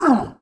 monster / greenfrog_general / demage_1.wav